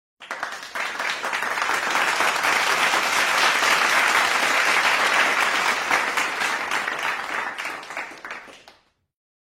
Applause